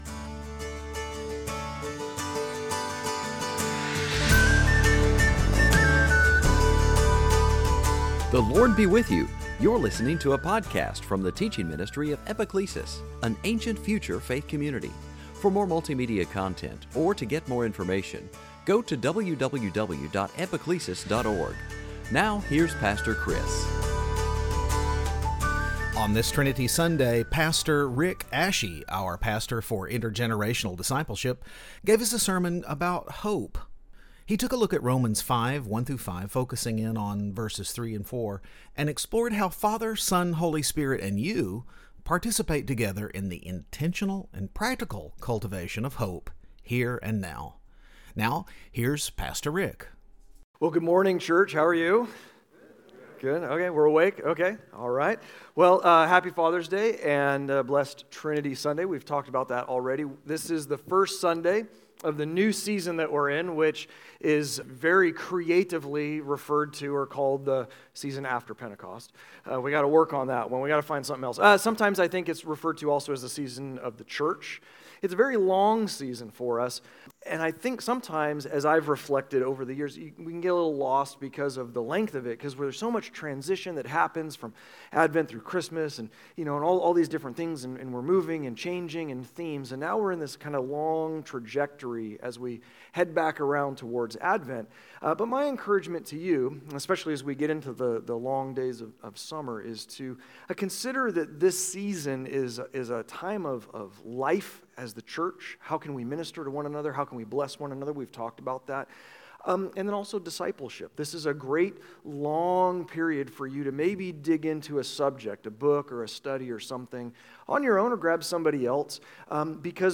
Series: Sunday Teaching
Romans 5:1-5 Service Type: Trinity Sunday Bible Text